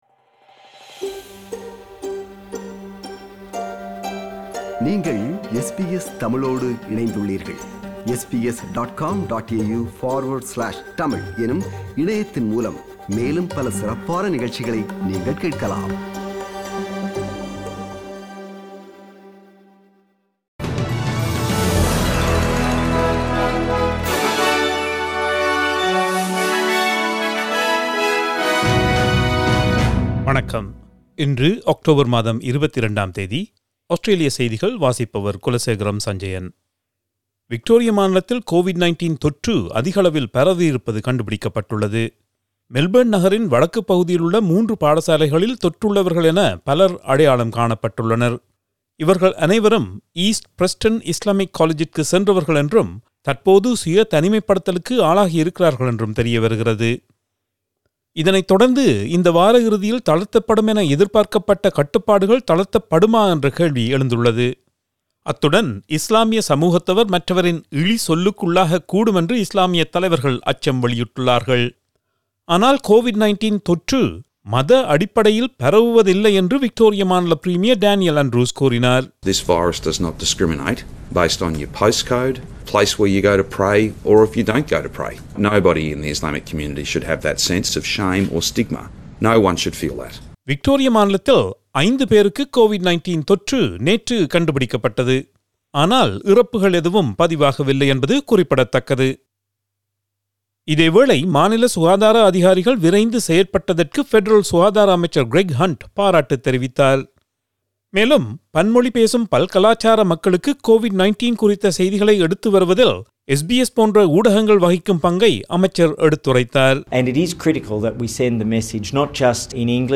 Australian news bulletin for Thursday 22 October 2020.